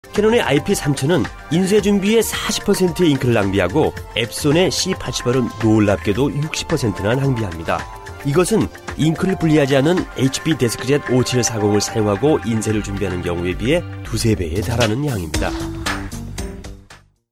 Professioneller koreanischer Sprecher für TV / Rundfunk / Industrie.
Sprechprobe: Industrie (Muttersprache):
Professionell korean voice over artist